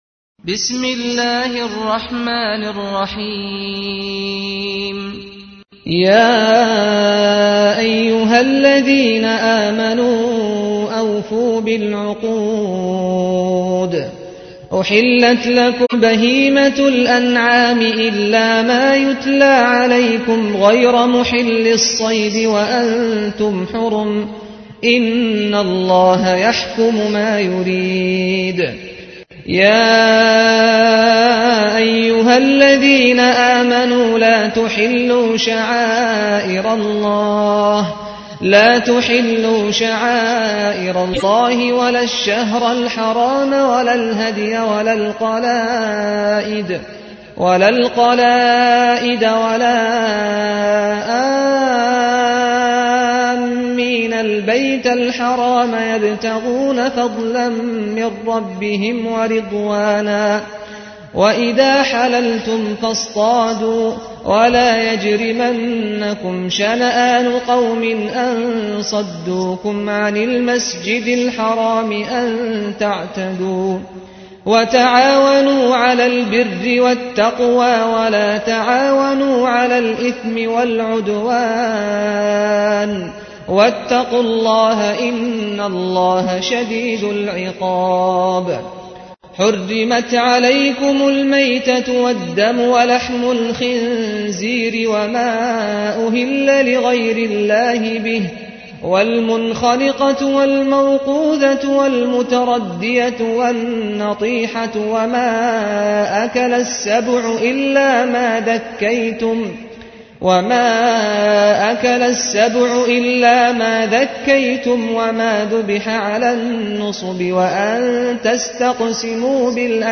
تحميل : 5. سورة المائدة / القارئ سعد الغامدي / القرآن الكريم / موقع يا حسين